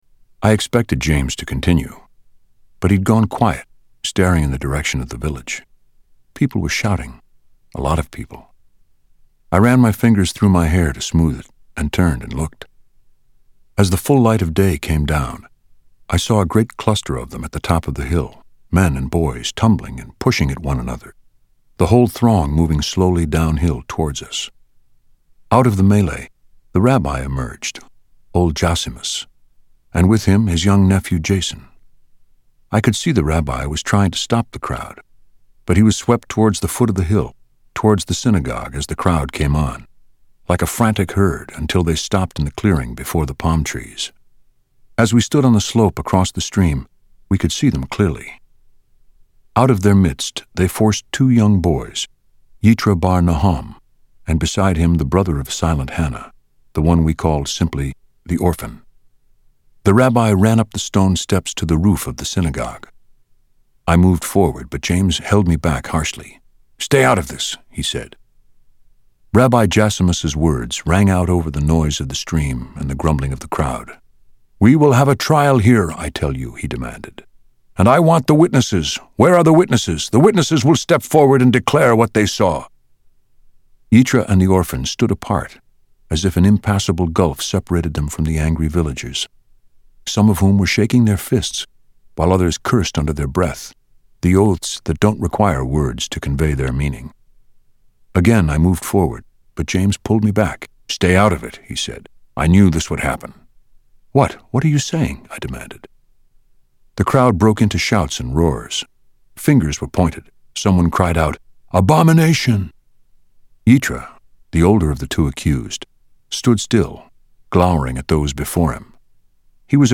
Tags: Media Author Anne Rice Interview with the Vampire Audio Books